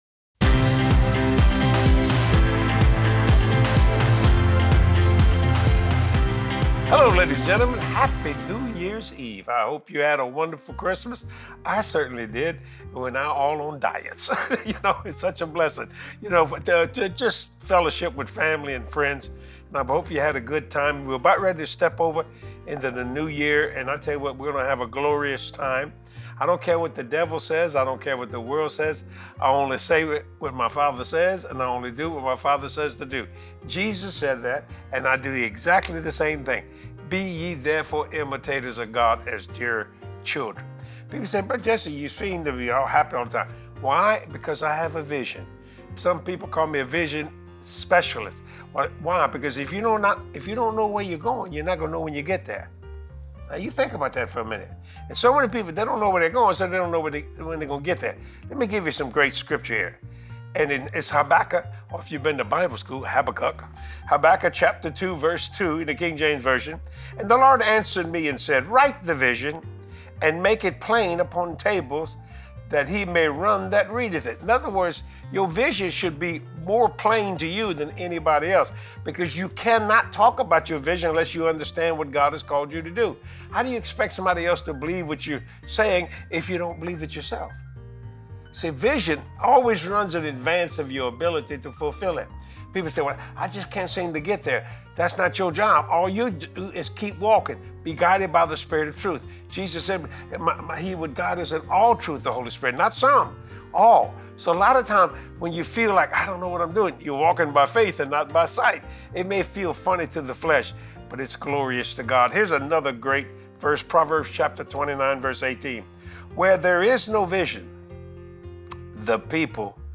Step into 2026 with your VISION expanded like never before! Watch this great teaching from Jesse and get excited for this new season of faith.